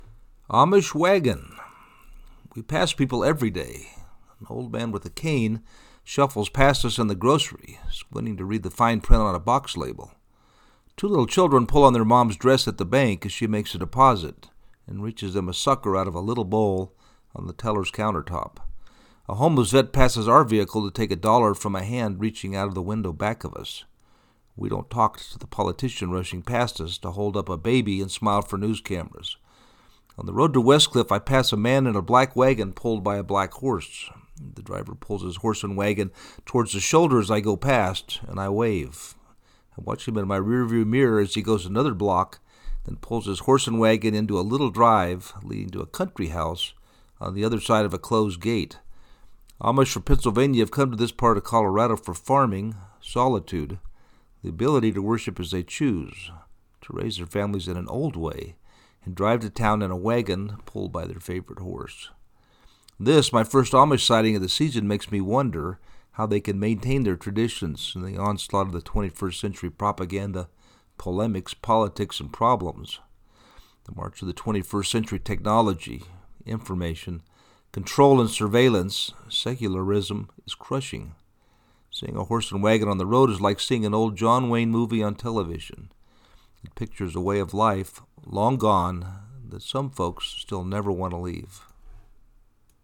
Amish wagon on the road to Westcliff, Colorado
On the road to Westcliff, I pass a man in a black wagon pulled by a black horse.
amish-wagon-2.mp3